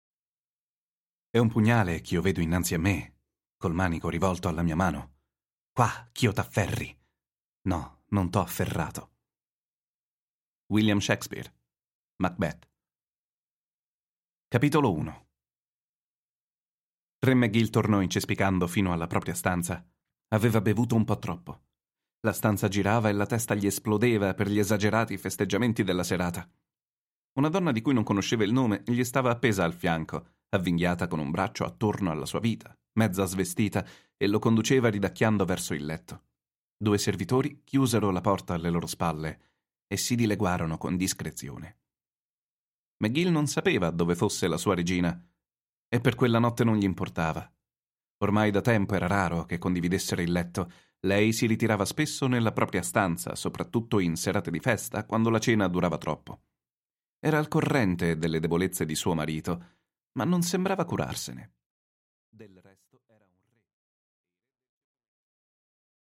Аудиокнига La Marcia Dei Re | Библиотека аудиокниг